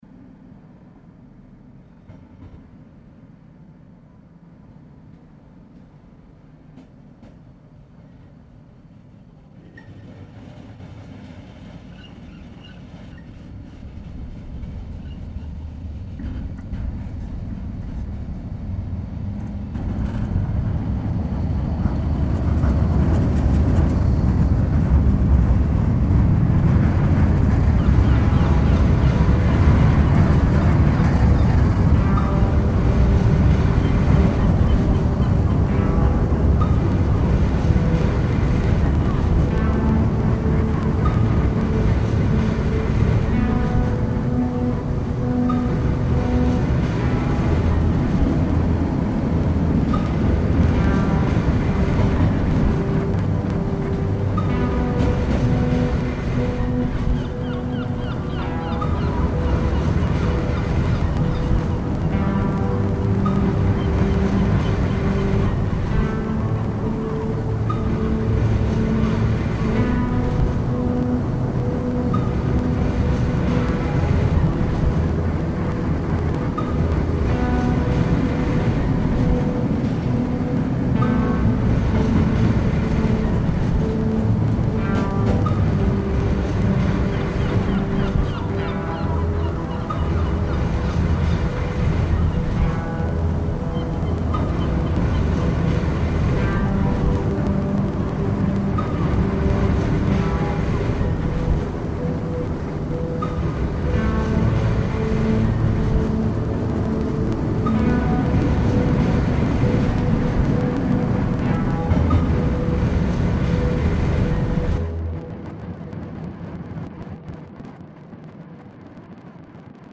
Dom Luis I Bridge, Porto